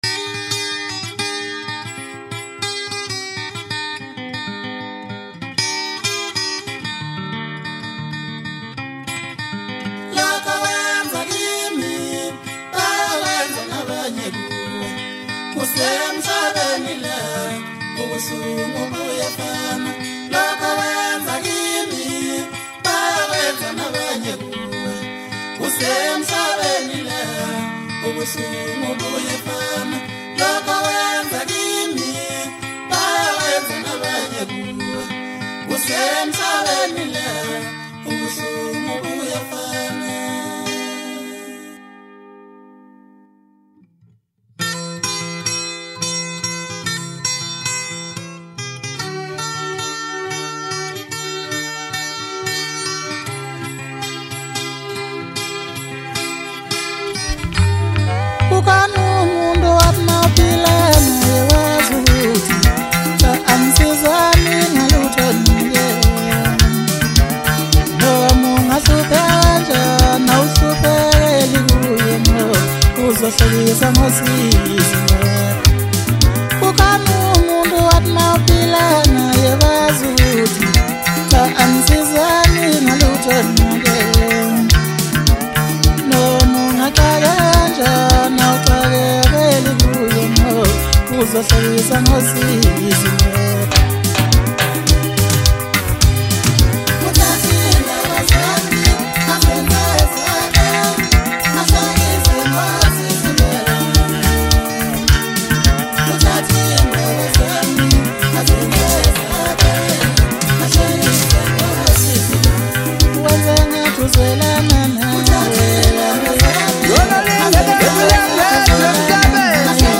Home » Maskandi